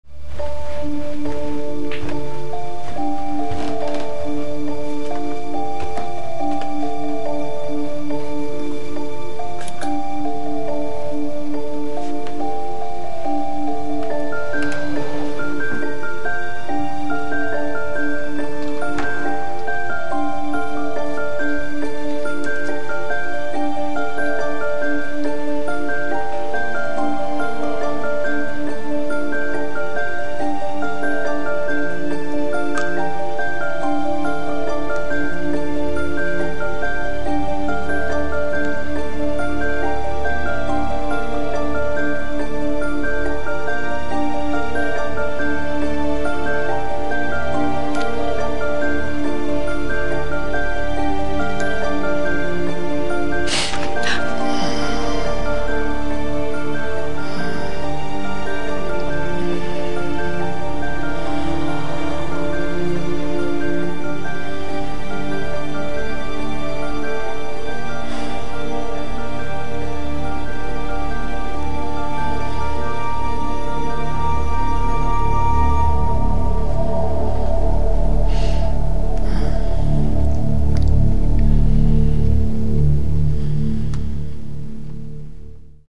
score is very muted